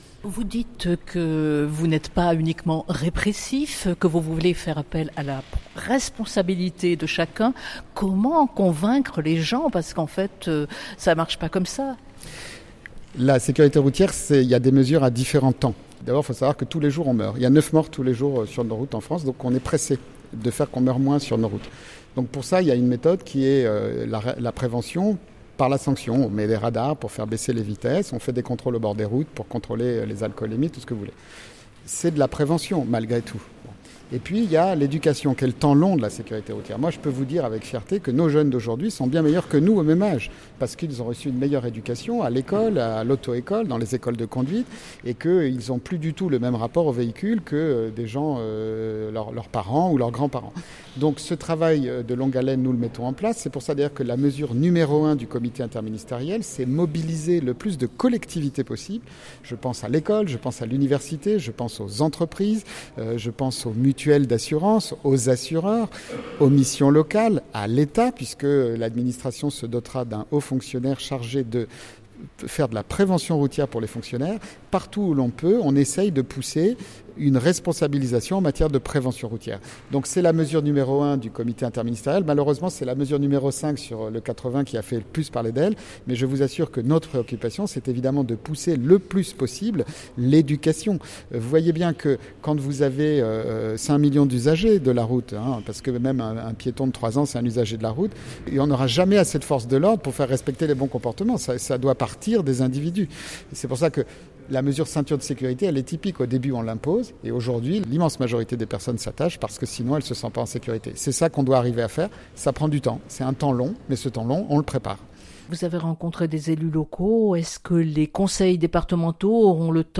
Sécurité routière – Entretien avec Emmanuel Barbe : “9 morts sur la route par jour en France”
emmanuel_barbe_delegue_interministeriel_a_la_securite_routiere.mp3